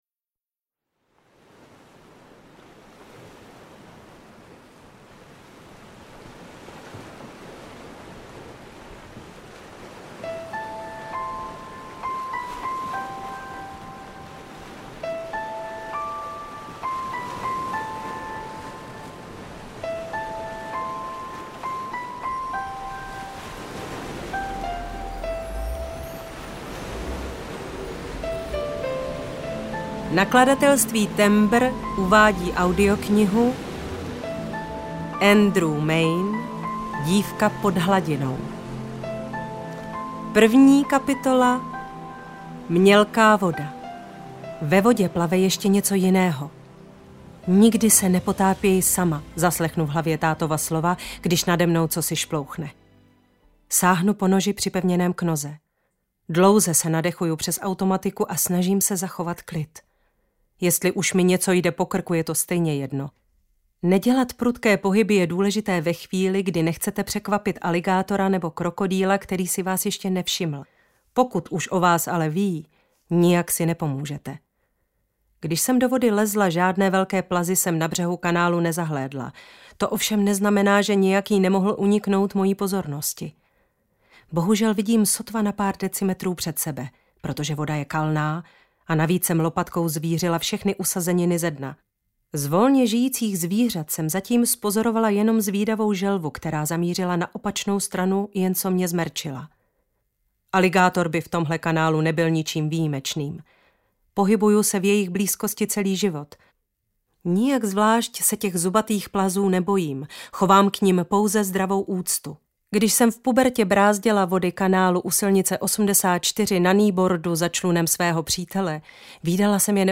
Dívka pod hladinou audiokniha
Ukázka z knihy
• InterpretBarbora Kodetová